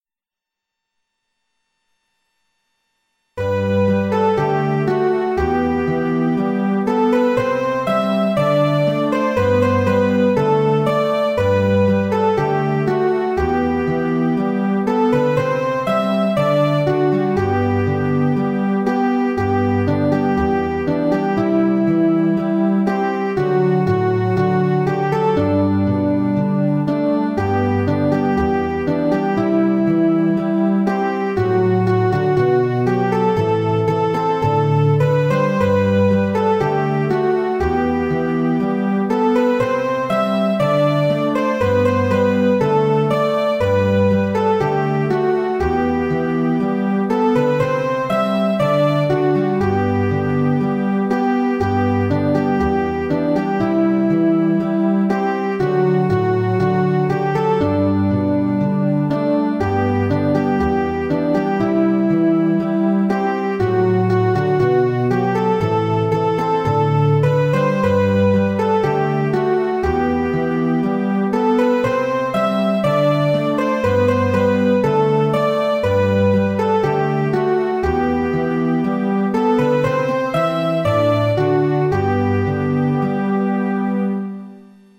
A quiet Psalm of thanks to God for his love and salvation.